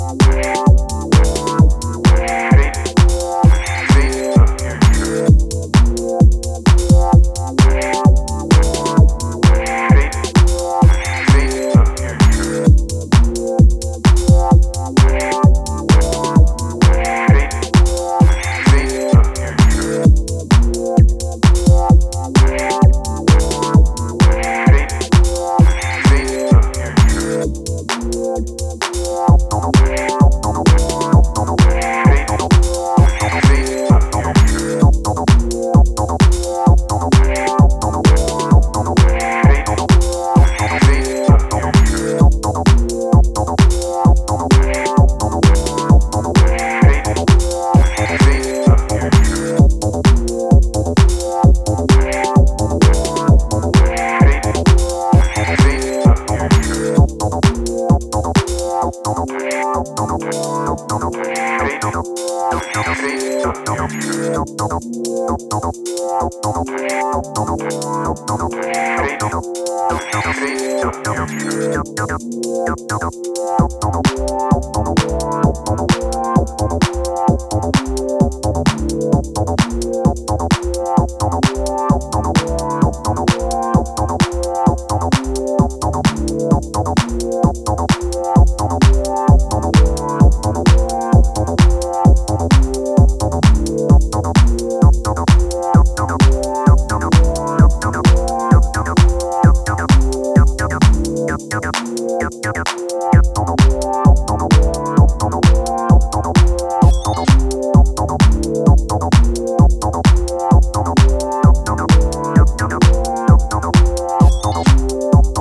Four pristine club moods for any dancefloor.